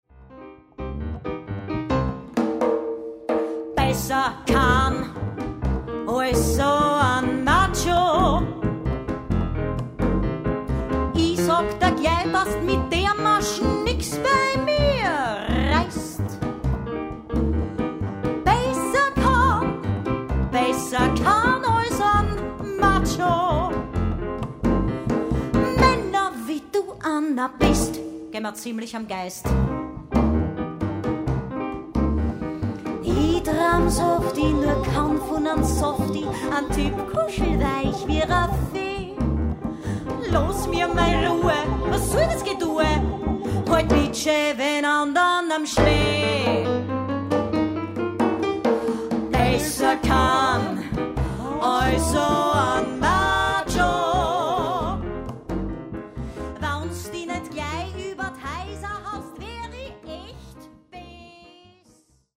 Stimmwunder & Percussion
Lead, Conférencier & Klavier, Gitarre
Stimmakrobat & Kontrabass